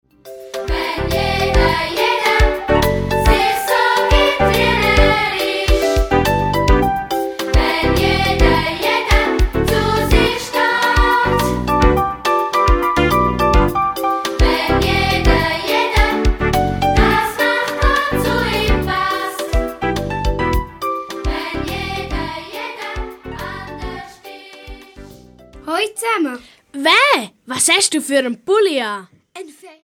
Musical - CD mit Download-Code